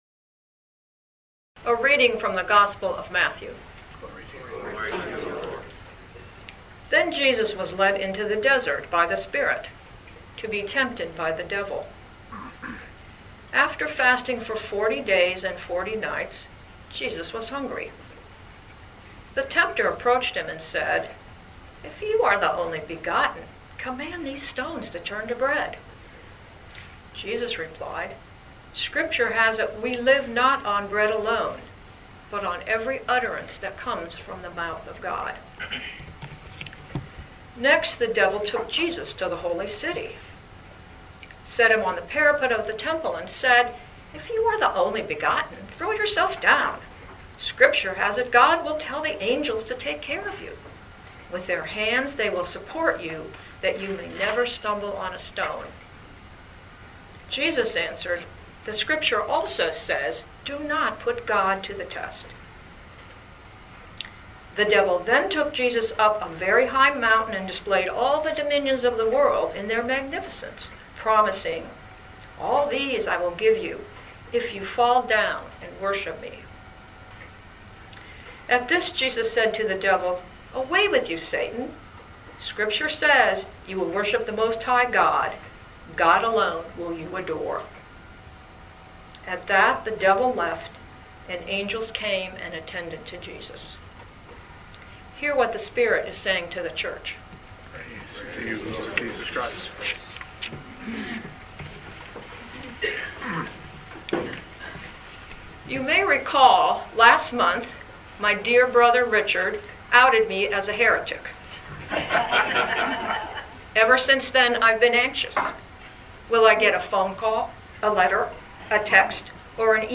Living Beatitudes Community Homilies: Be-Attitudes: Lent and the Call to Love